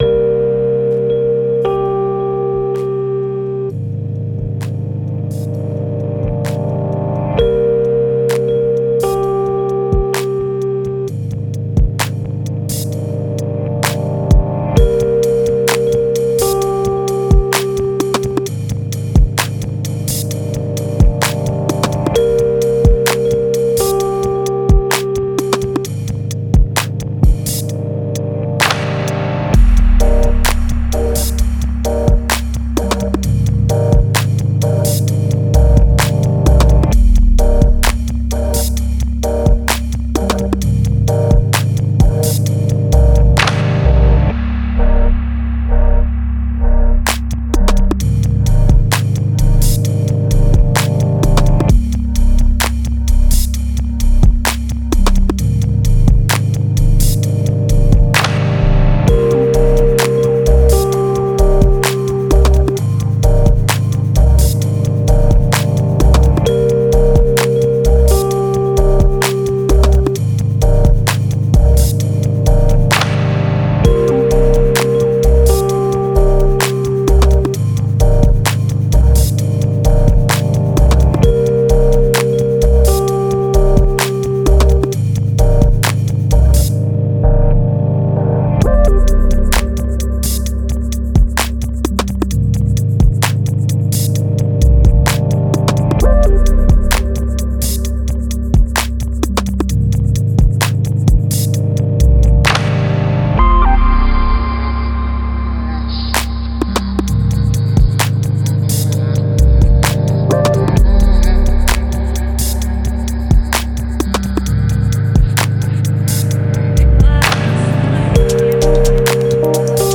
Genre: Deep Dubstep, Dub, Electronic.